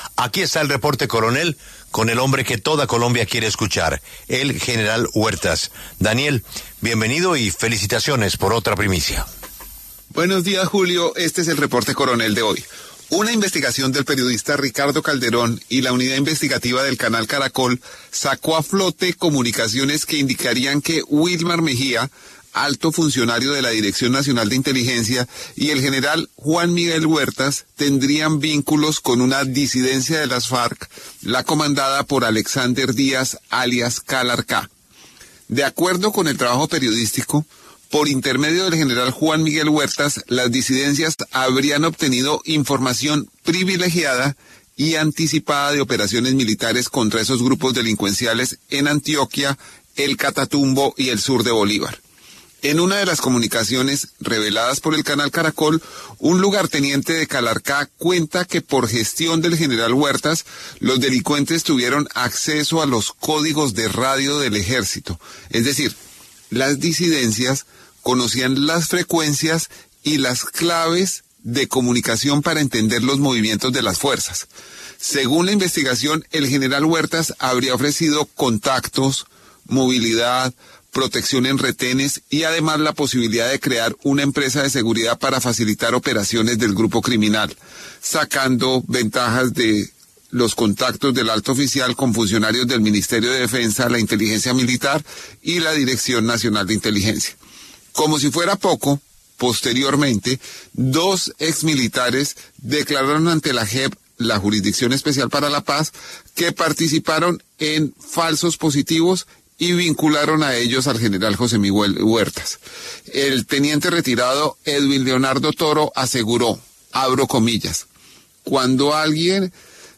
El alto oficial, suspendido por la Procuraduría, por primera vez concede una entrevista sobre los hechos.